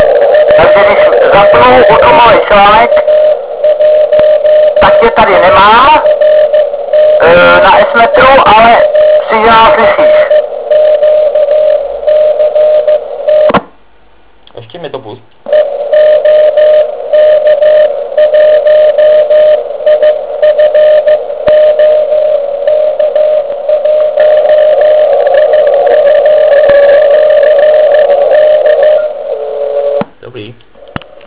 Podle S metru jsme naměřili, že signál oproti skutečné anténě je slabší asi o 30 dB.
Vyzářený výkon asi 1mW na 3559.5 KHz na vzdálenost 5 Km